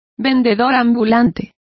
Complete with pronunciation of the translation of huckster.